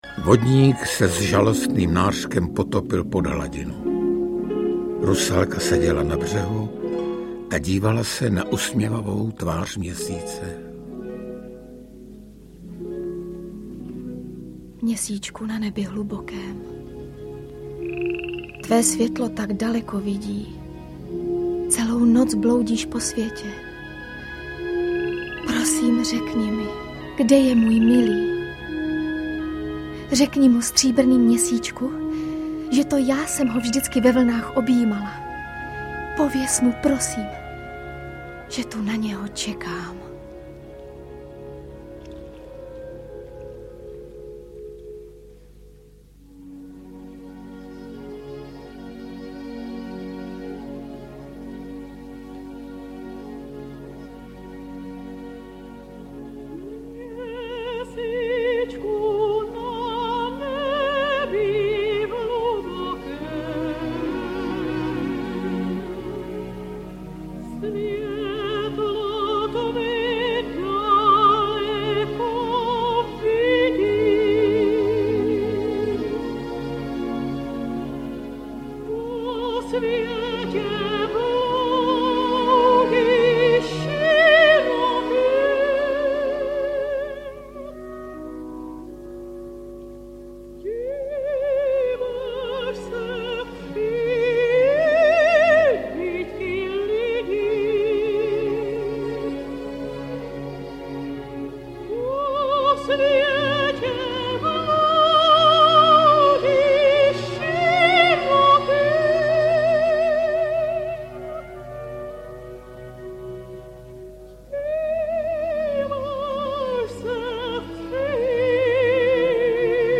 Audiokniha Nebojte se klasiky 10 - Rusalka, autorem je Antonín Dvořák.
Ukázka z knihy